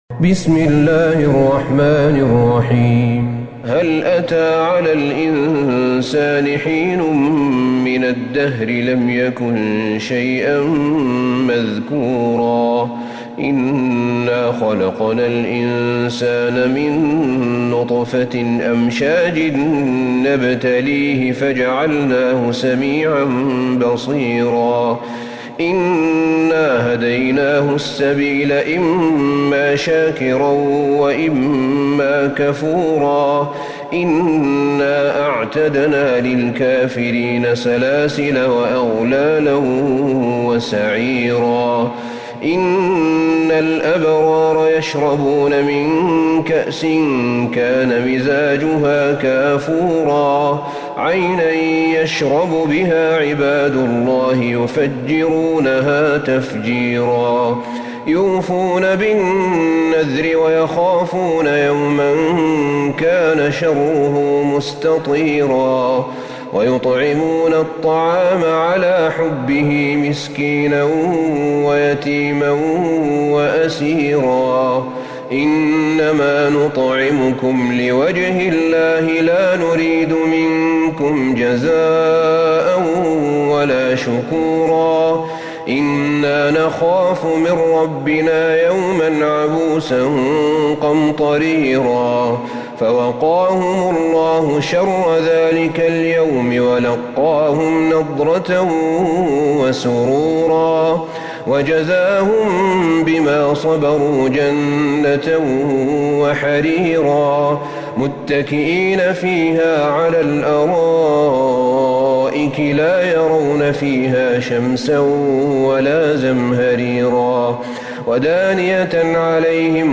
سورة الإنسان Surat Al-Insan > مصحف الشيخ أحمد بن طالب بن حميد من الحرم النبوي > المصحف - تلاوات الحرمين